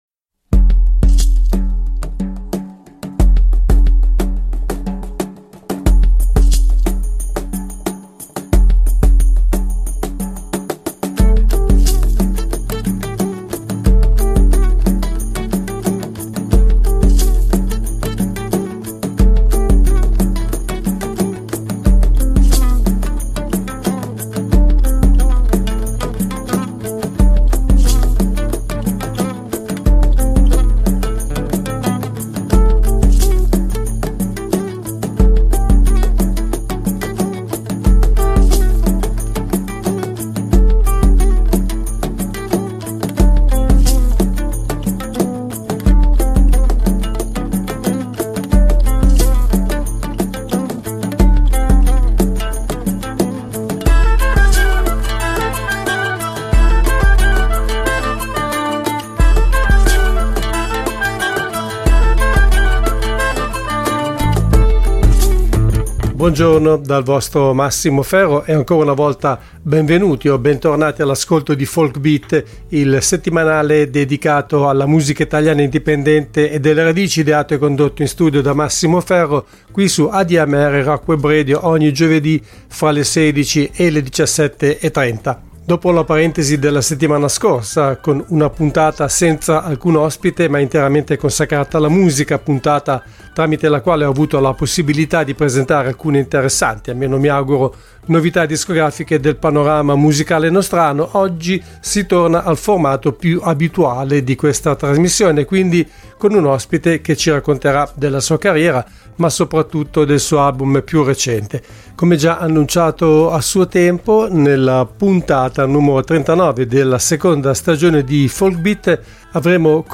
Part I: �Folk Beat� (08.06.2023) Ospite del programma al telefono